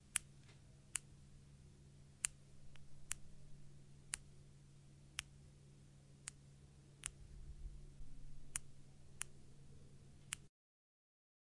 切割钉子
描述：手指甲被记录下的声音......
Tag: OWI 切削 手指甲